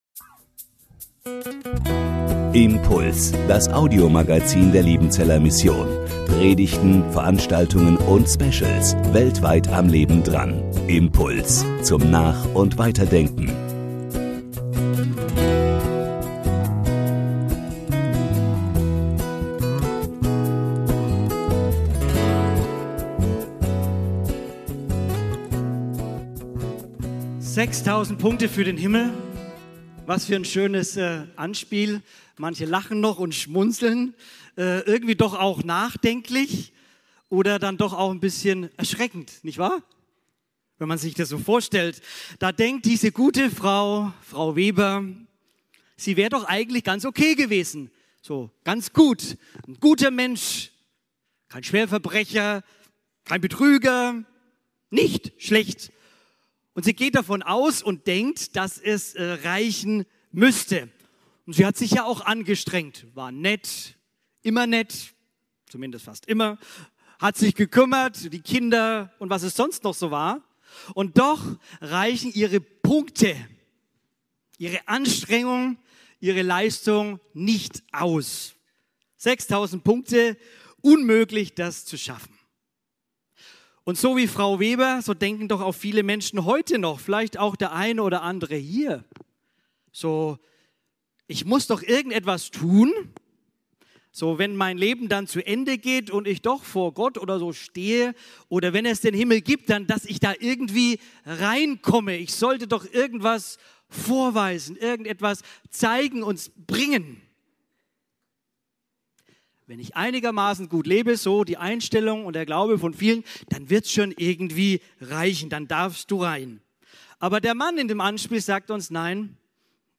MBG-Gottesdienst
Predigt